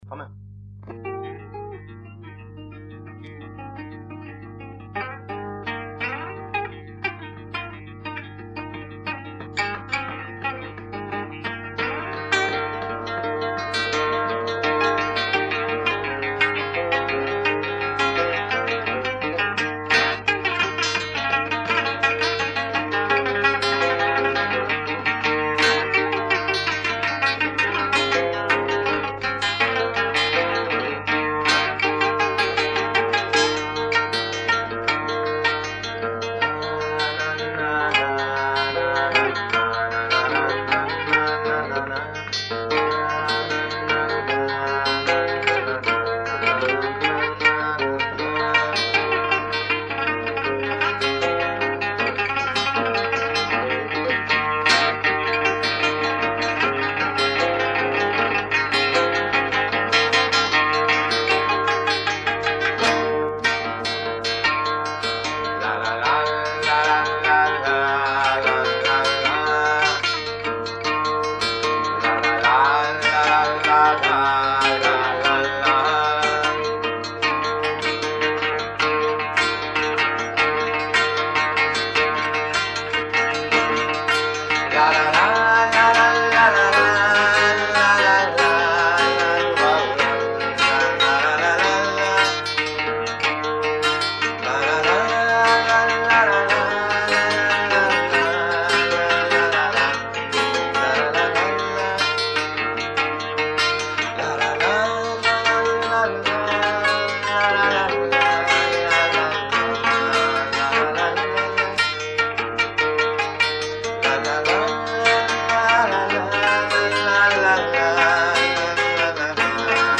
It’s a rehearsal